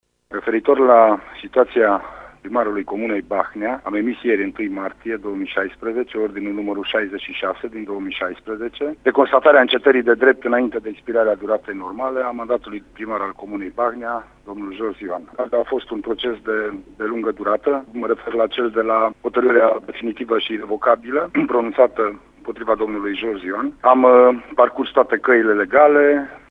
După o serie de demersuri legale care au inclus şi plângeri la DNA şi Parchet, ordinul a fost semnat ieri, a declarat pentru RTM prefectul judeţului Mureş, Lucian Goga: